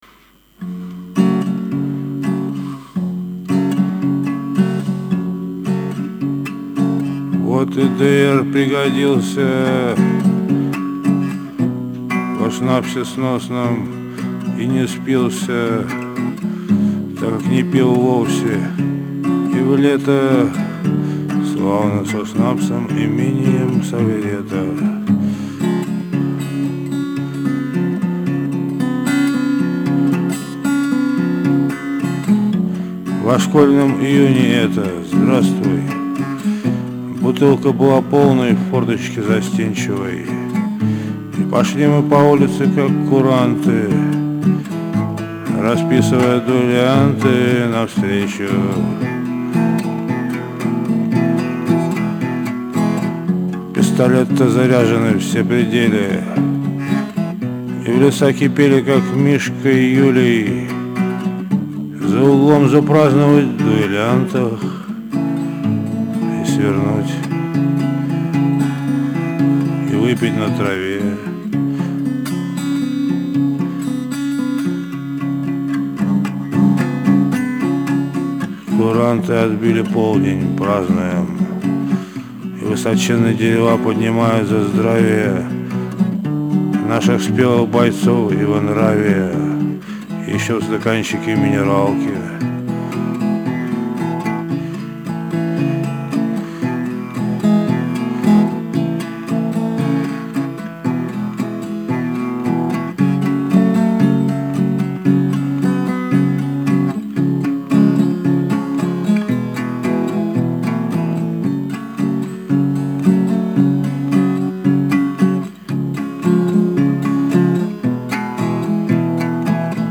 с другим ревербом